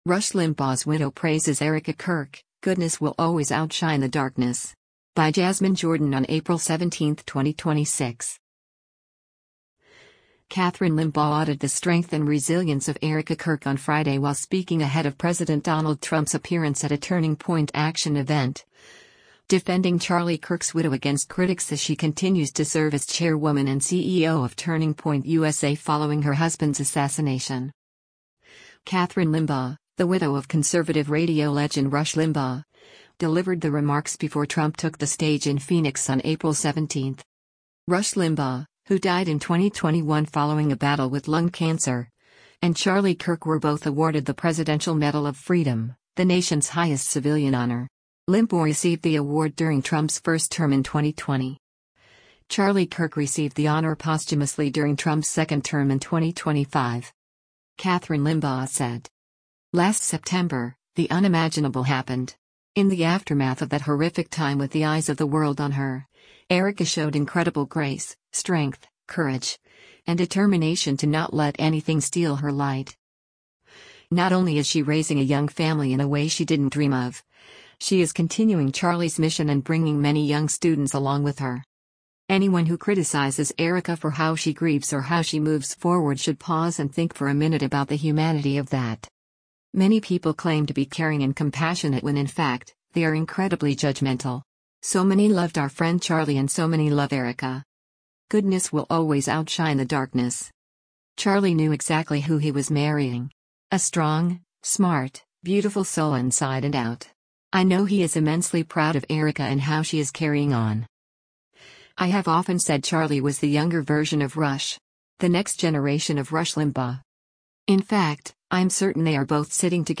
Kathryn Limbaugh, the widow of conservative radio legend Rush Limbaugh, delivered the remarks before Trump took the stage in Phoenix on April 17.